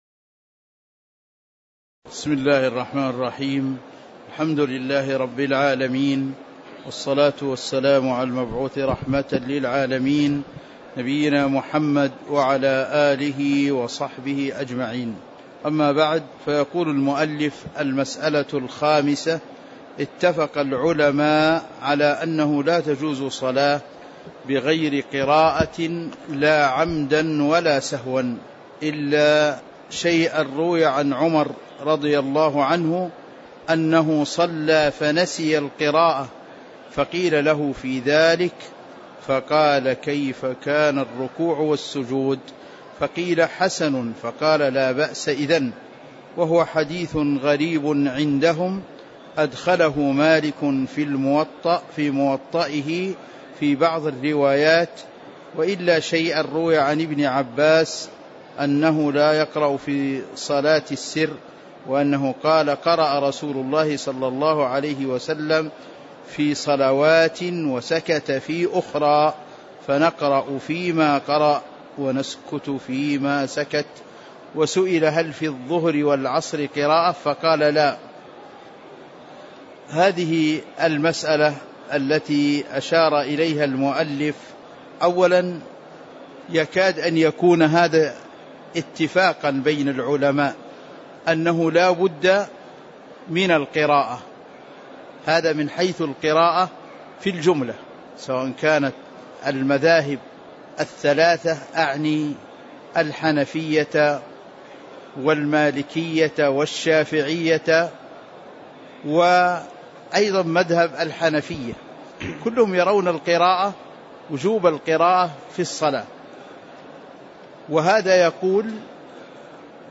تاريخ النشر ٥ ربيع الثاني ١٤٤١ هـ المكان: المسجد النبوي الشيخ